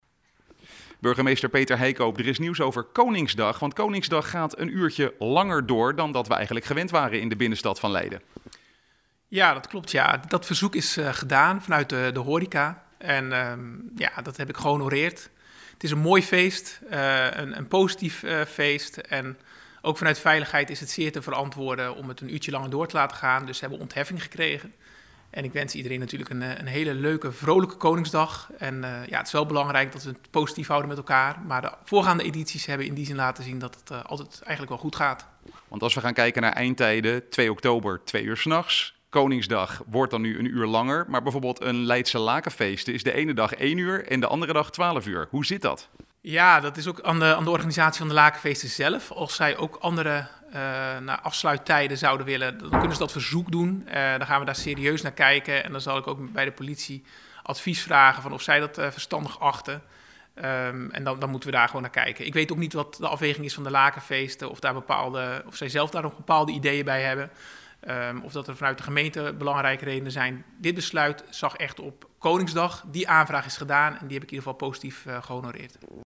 Burgemeester Peter Heijkoop over het uurtje langer feest tijdens Koningsdag: